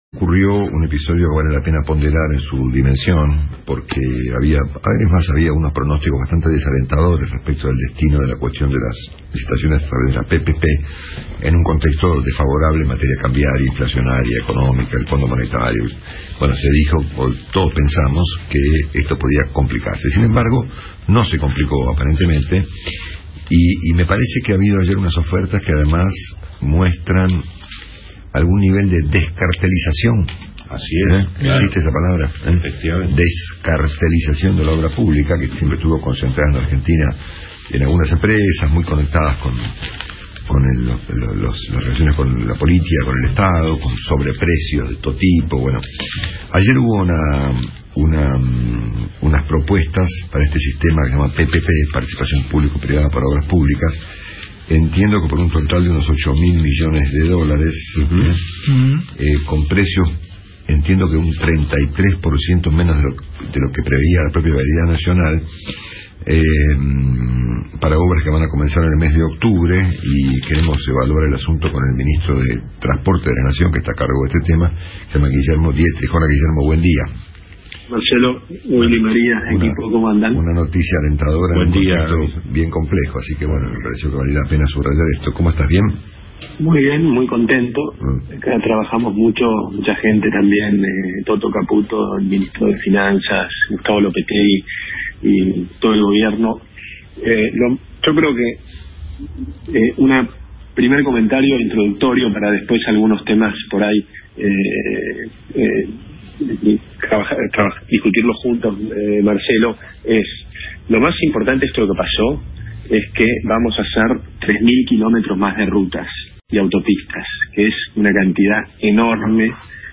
El ministro de Transporte de la Nación, Guillermo Dietrich, habló en Cada Mañana por Radio Mitre sobre el avance y la descartelización de la obra pública en Argentina.